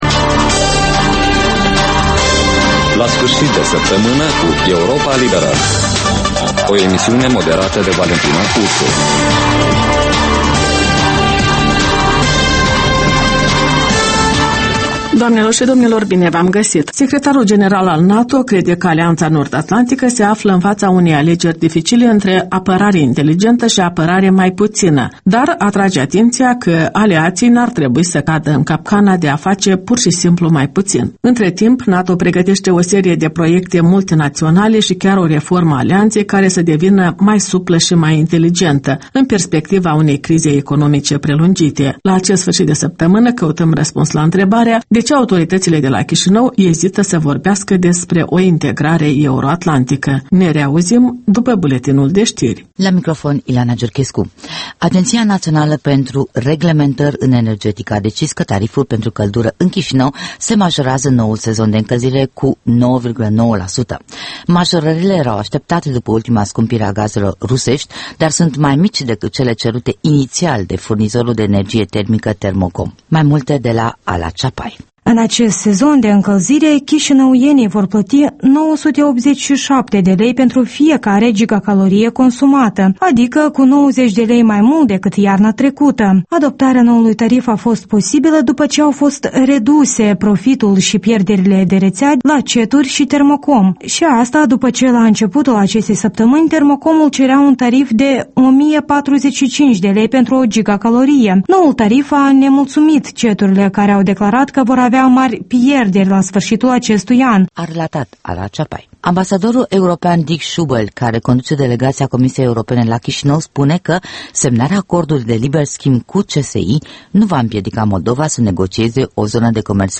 In fiecare sîmbătă, un invitat al Europei Libere semneaza "Jurnalul săptămînal".